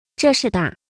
描述：中国大陆女声"这是大" (zhe shi da 这是大)
Tag: 140 bpm Spoken Word Loops Vocal Loops 36.14 KB wav Key : Unknown